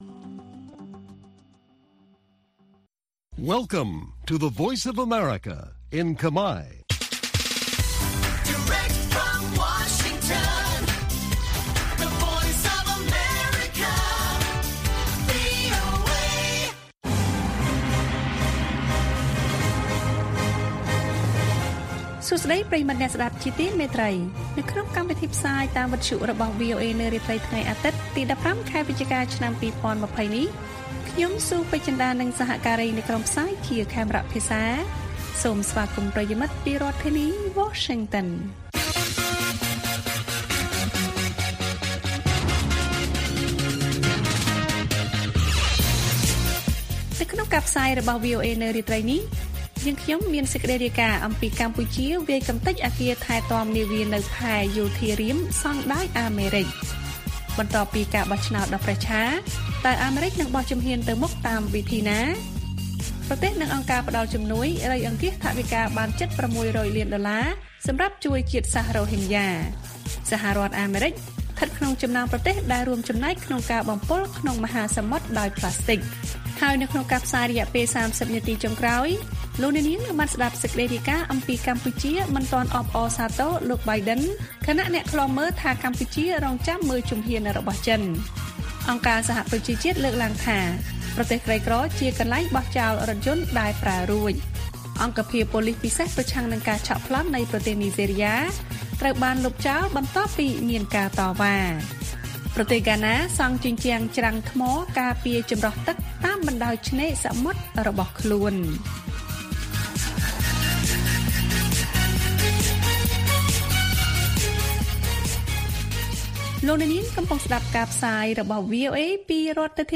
ព័ត៌មានពេលរាត្រី៖ ១៥ វិច្ឆិកា ២០២០